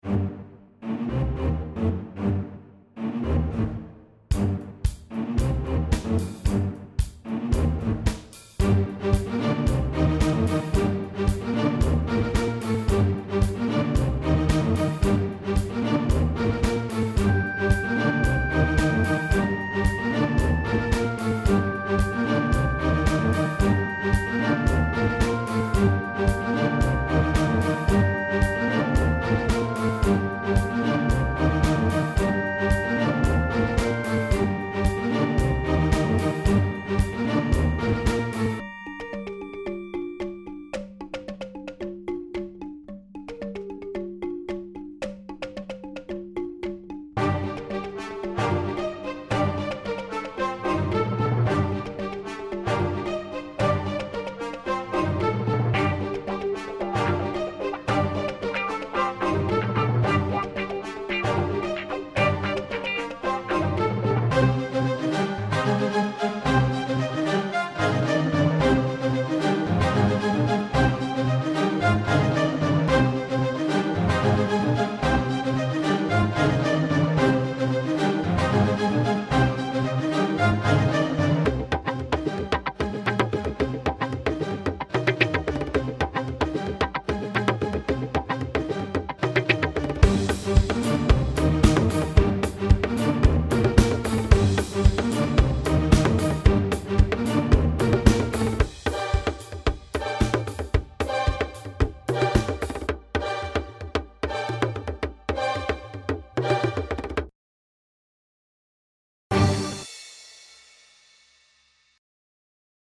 An Instrumental – "In Hot Pursuit"!
I’m learning how to create and record music with Garageband.* Here’s my first instrumental using Apple Loops*. It has the feel of a chase scene in a movie.